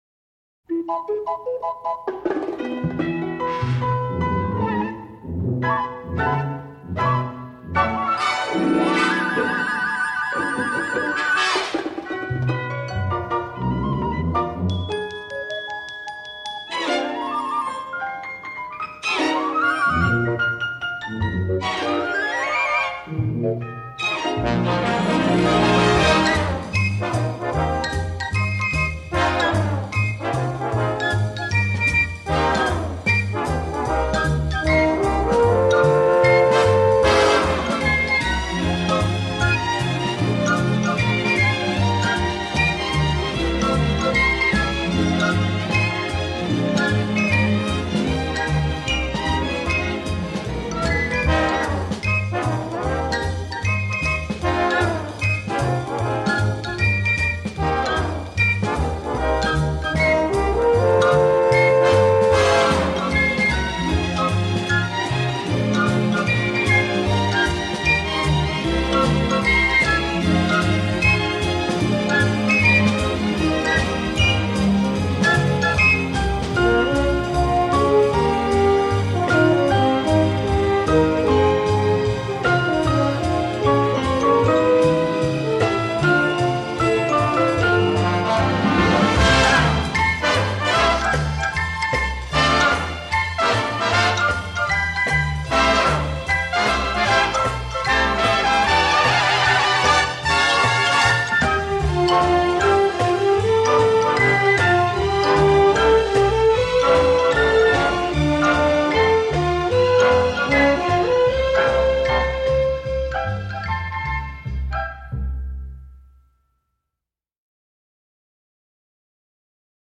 cette partition légère, un brin mancinienne